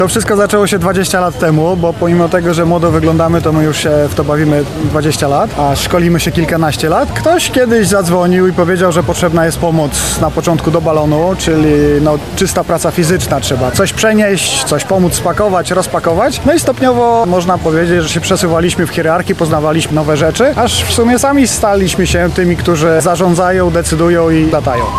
Festyn Pozytywnie Zakręconych